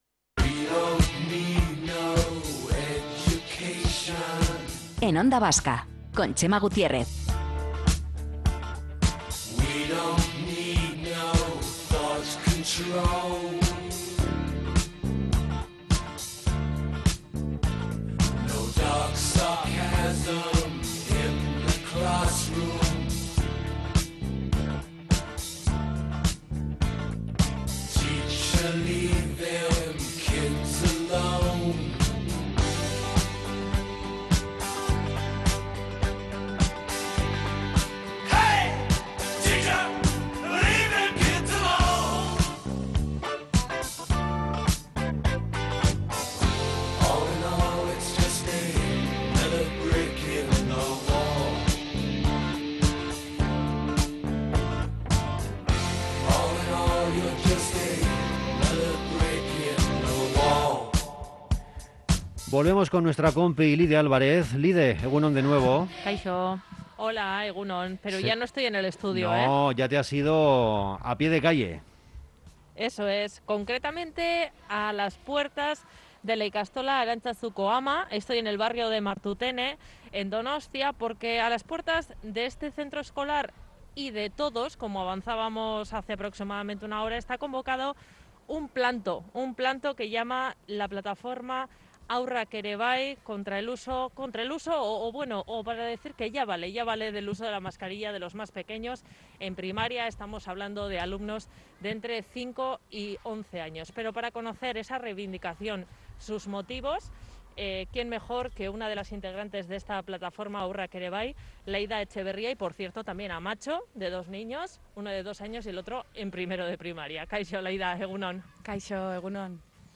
A las puertas de la ikastola Jakintza, en el barrio del Antiguo, en Donostia, la mayor parte de los aitas y amas se mostraba favorable a la relajación de las medidas frente a la pandemia en Primaria.